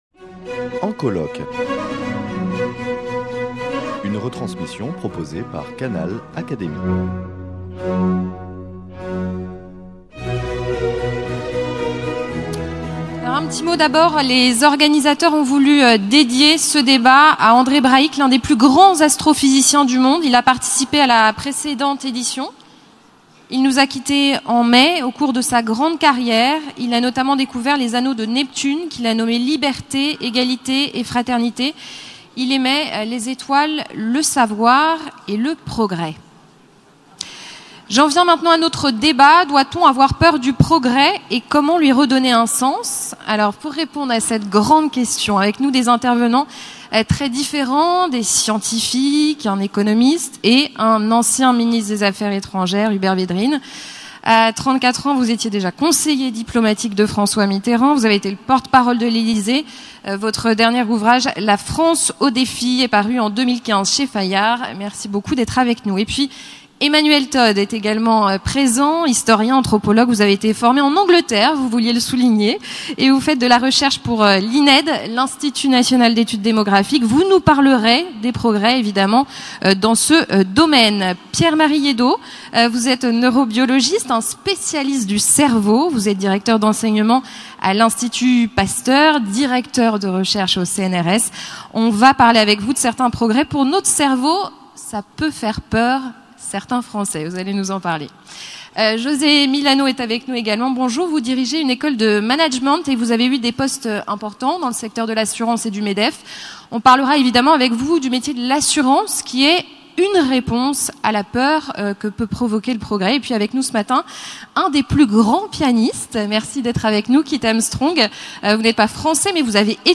Débat des Rencontres capitales 2016 de l’Académie des sciences